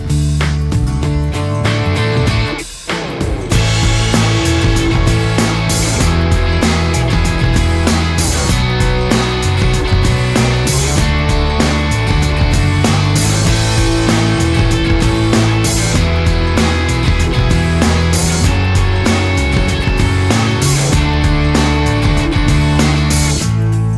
no Backing Vocals Pop (1990s) 3:55 Buy £1.50